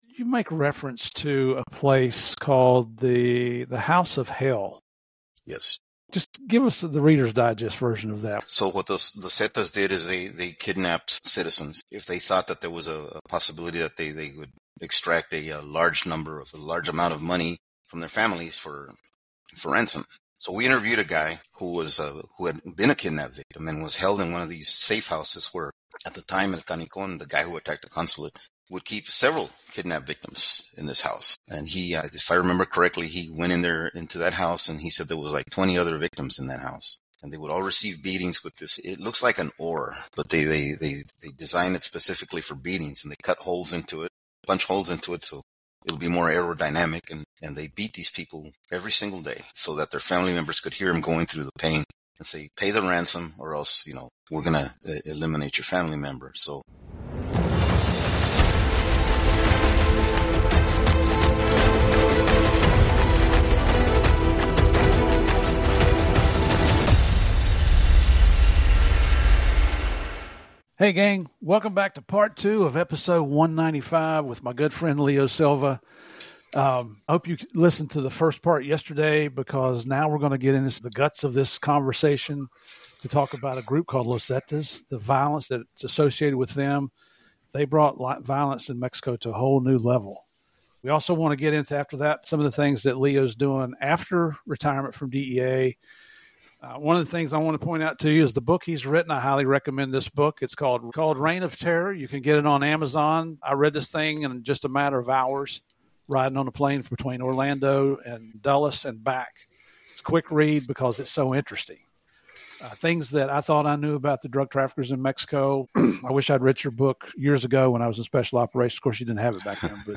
This intense conversation reveals the dark side of tourist hotspots, the true cost of drug trafficking, and why public awareness is critical.